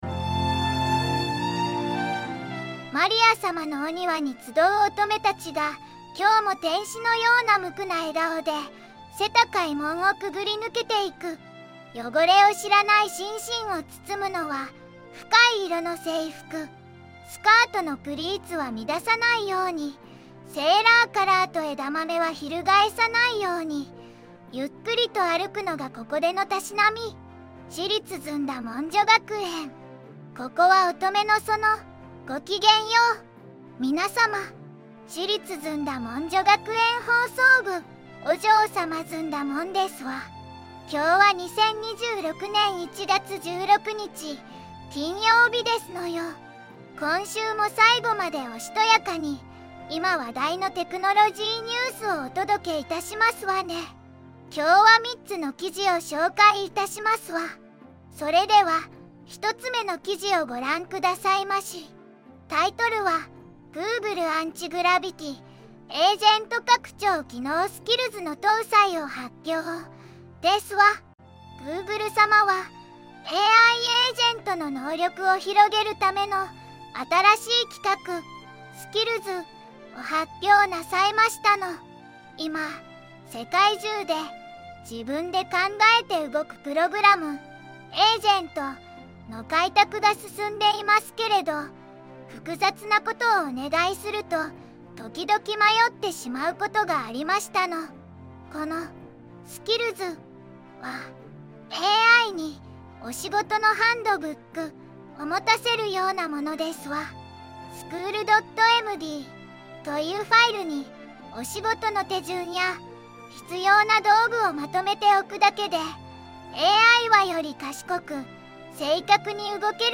VOICEVOX:ずんだもん